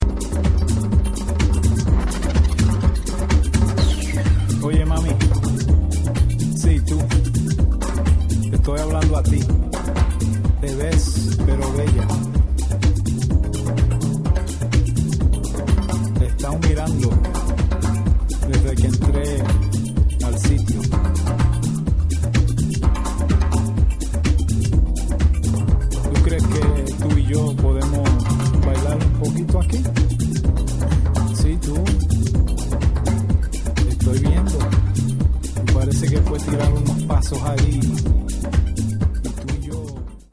Vocal Mix
Timb-strumental
Acapella